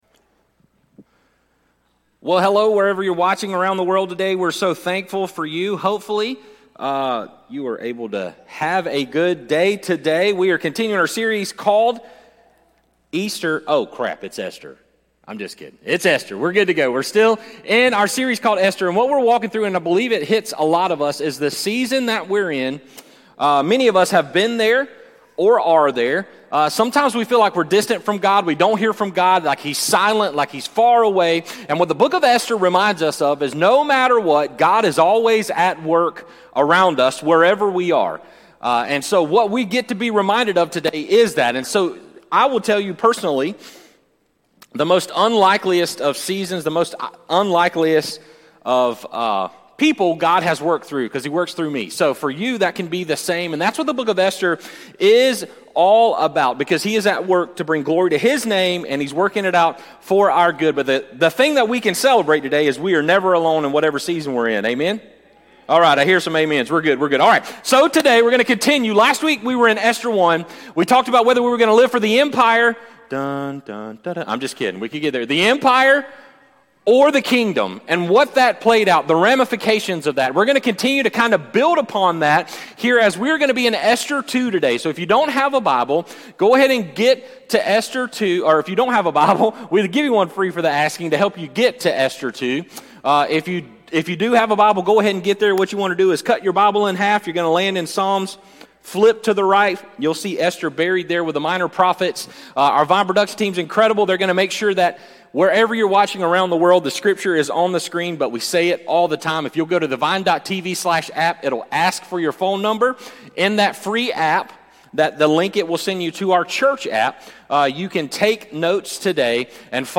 Sermons | The Vine Church